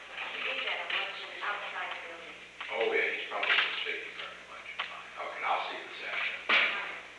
Secret White House Tapes
Conversation No. 735-11
Location: Oval Office
The President met with an unknown woman.